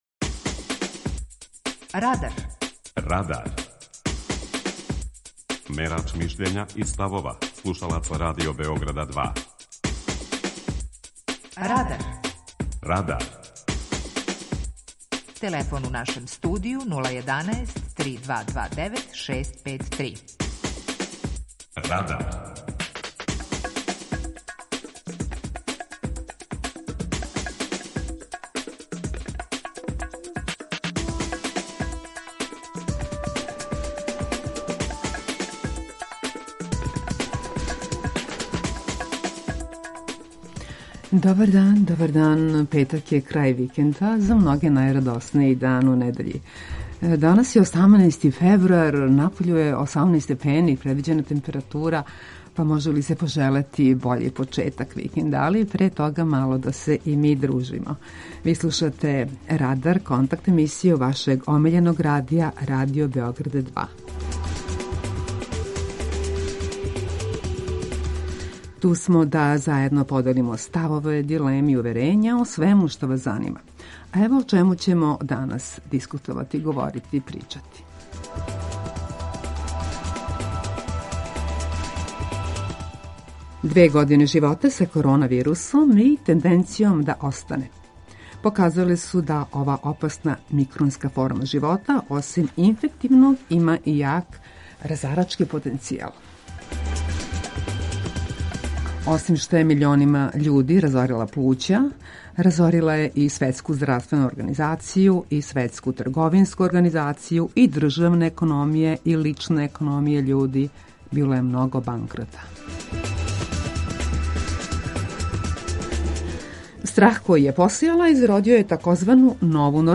Питање за данас гласи: Kако сте сачували ведар и здрав дух у време короне? преузми : 19.17 MB Радар Autor: Група аутора У емисији „Радар", гости и слушаоци разговарају о актуелним темама из друштвеног и културног живота.